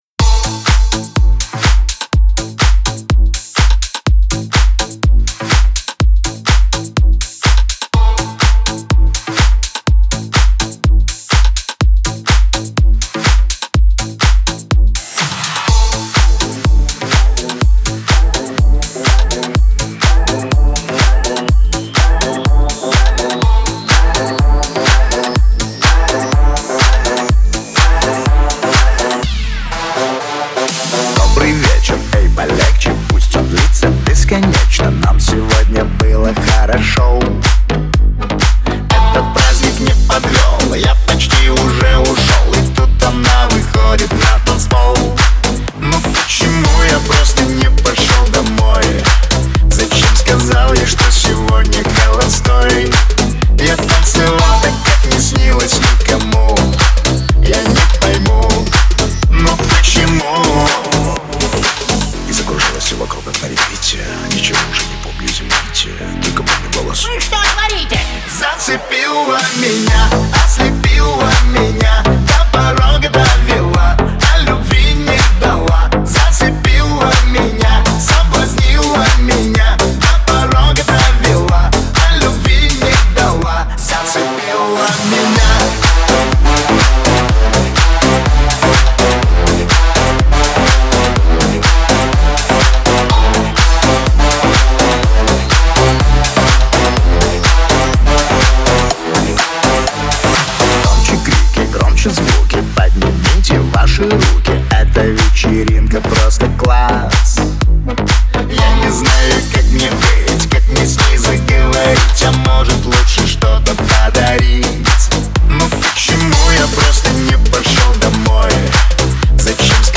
это зажигательная композиция в жанре поп и электроника
ритмичные биты и мелодичный вокал